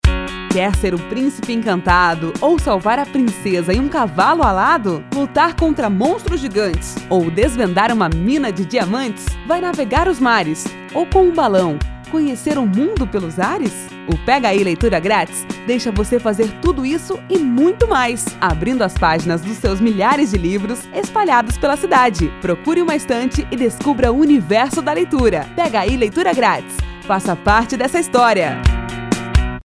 Novo Spot de rádio divulga Pegaí
Mergulhe no universo da leitura com o Pegaí! Novo comercial destinado às rádios da cidade conta um pouquinho do que se pode encontrar somente folheando algumas páginas…